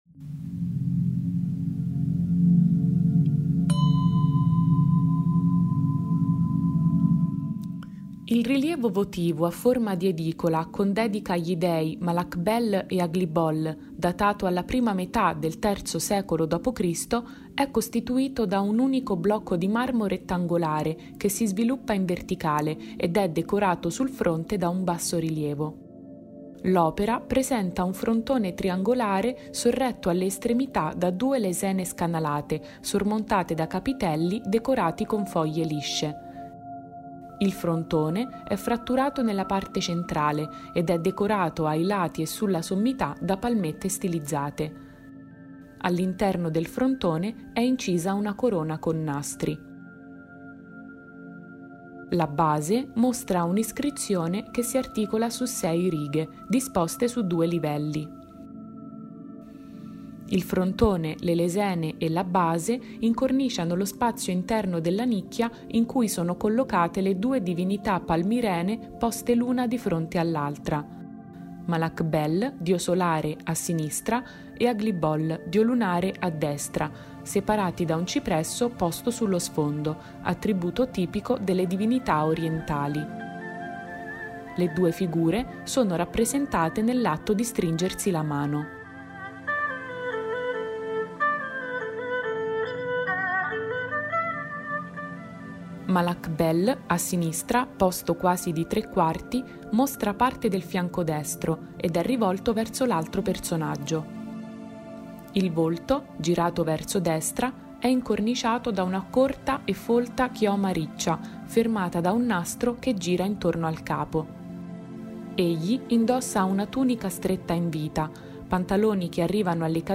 The reading is accompanied by musical fragments and sound references that suggest details of the story, stimulating the imagination and promoting knowledge of the work through immersive perception.
Audio description in Italian (.mp3)